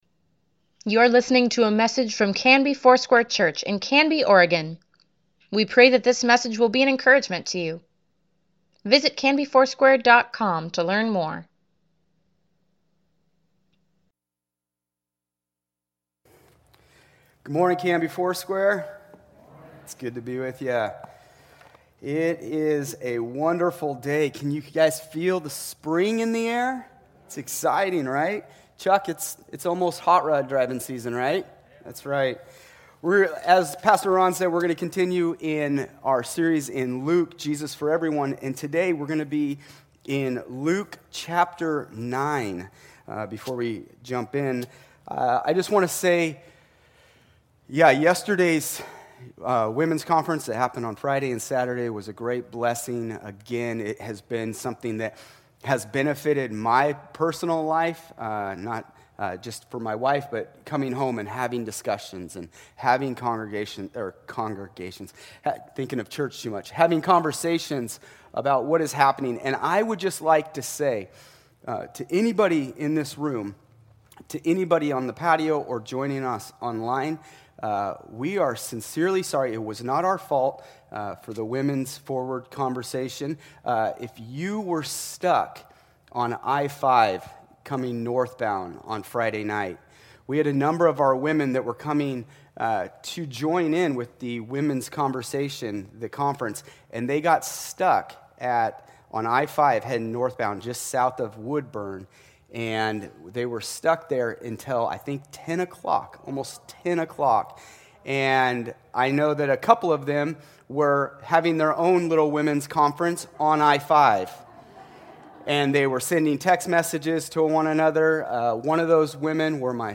Weekly Email Water Baptism Prayer Events Sermons Give Care for Carus Jesus for Everyone, pt.9 February 28, 2021